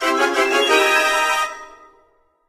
laser_panic_01.ogg